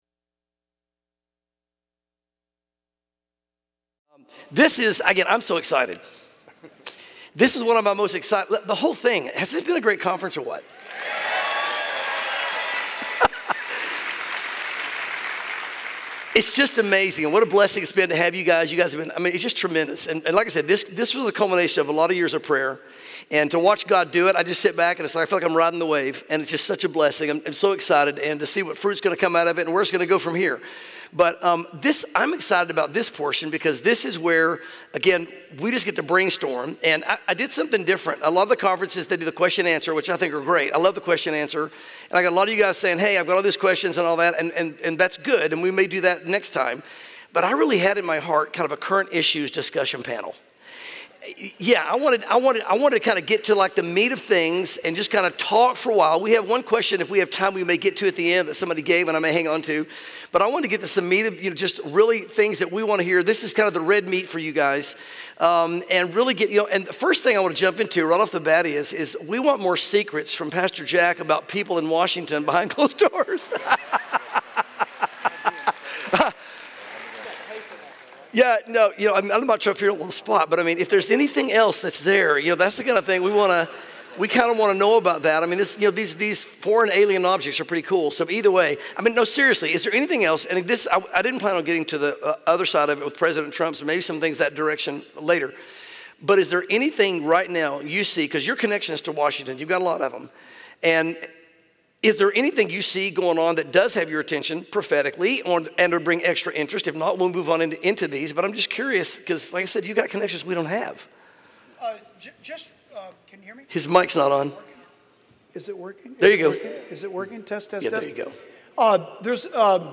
Session 5 | Q&A / Current Events Panel